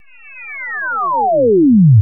MB Drop Effect (1).wav